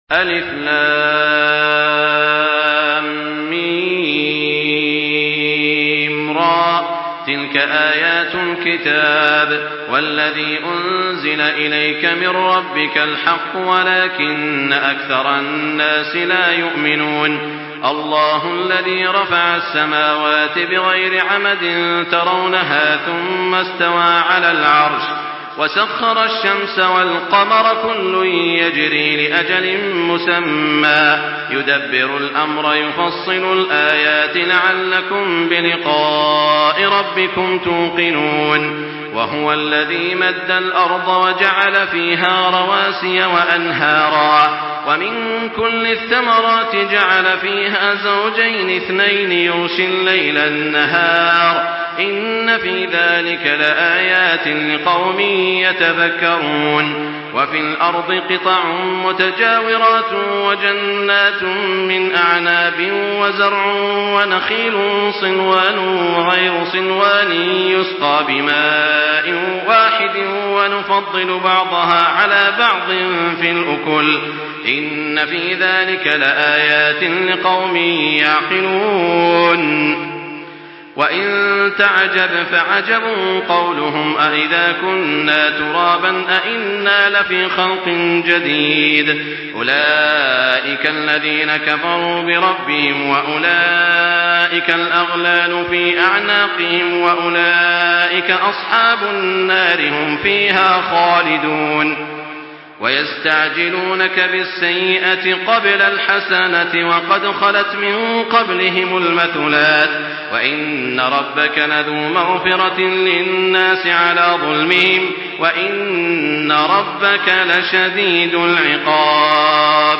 Surah আর-রা‘দ MP3 by Makkah Taraweeh 1424 in Hafs An Asim narration.